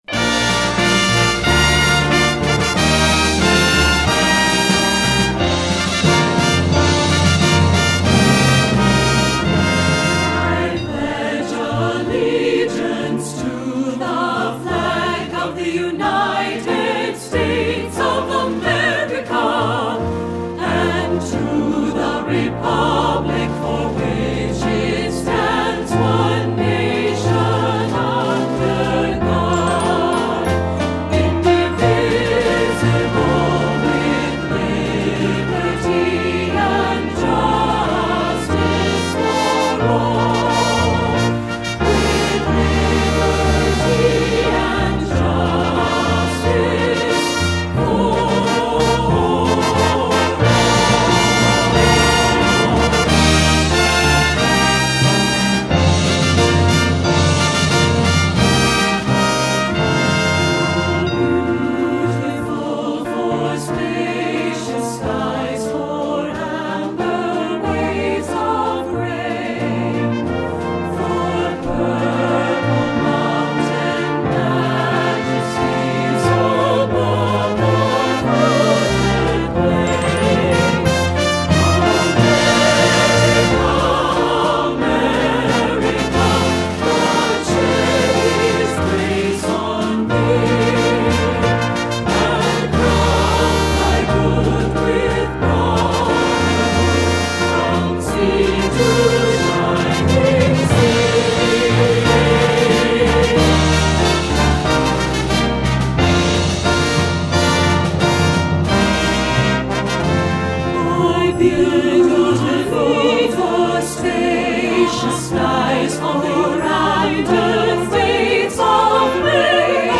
secular choral, american